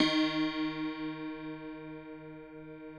53r-pno07-D1.aif